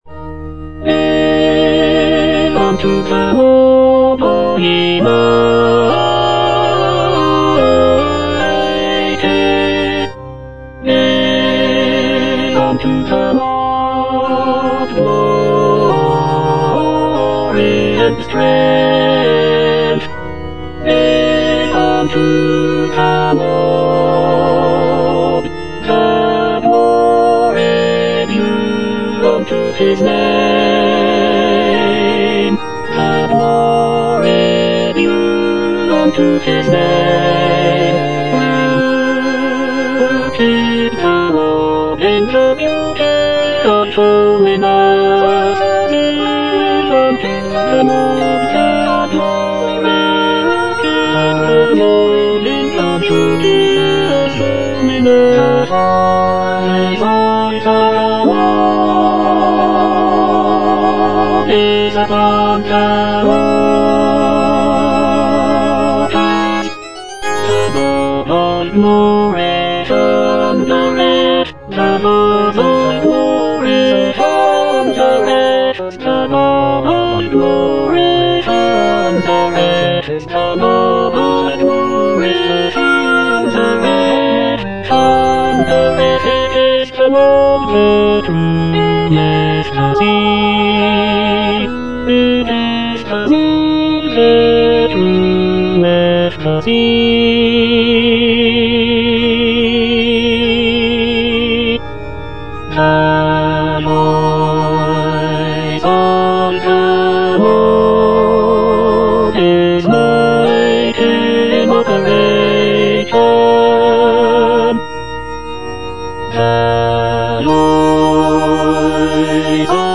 E. ELGAR - GIVE UNTO THE LORD Tenor II (Emphasised voice and other voices) Ads stop: auto-stop